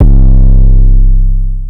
archived music/fl studio/drumkits/goodtakimu drumkit/808s